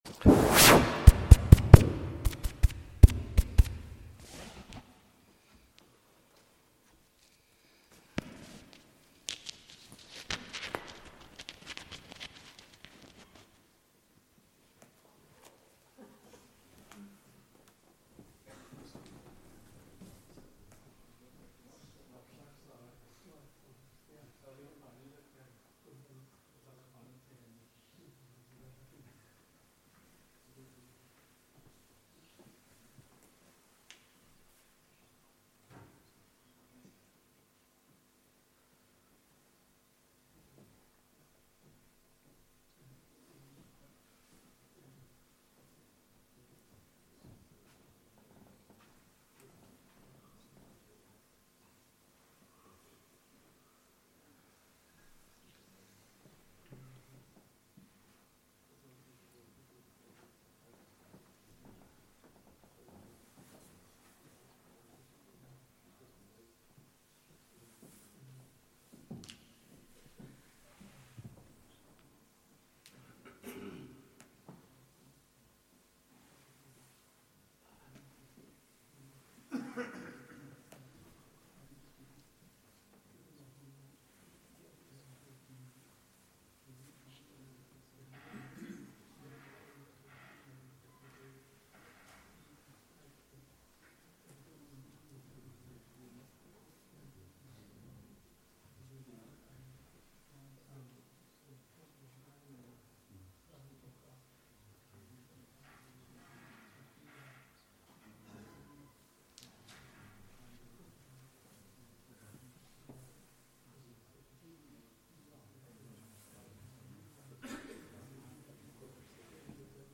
Salat al-Taraweeh.